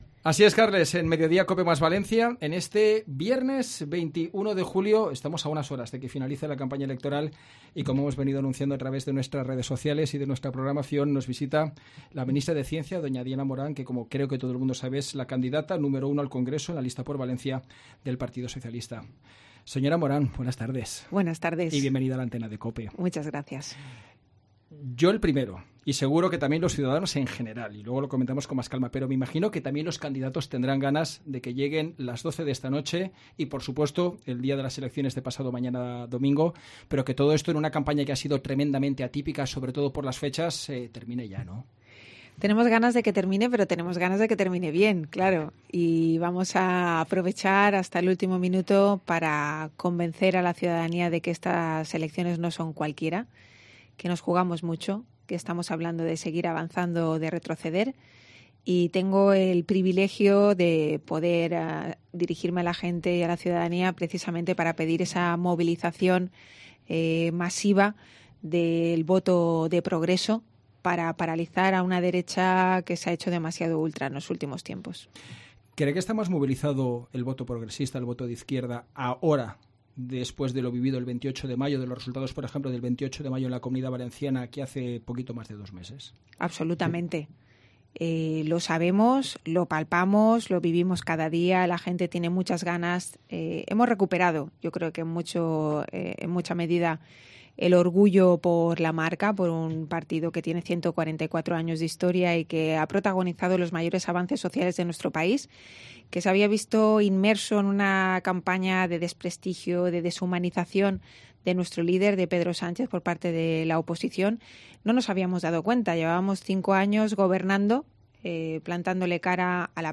Entrevista completa con Diana Morant